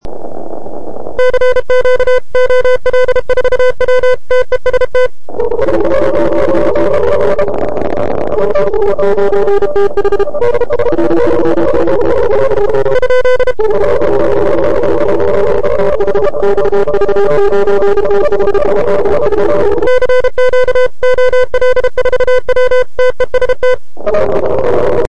pileup.mp3